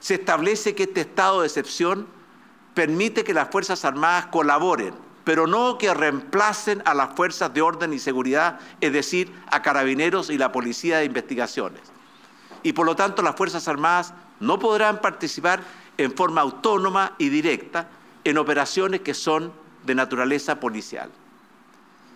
El anuncio fue realizado por el mandatario desde el Salón Montt Varas del Palacio de La Moneda ante los hechos de violencia que afectan y han afectado al sur del país.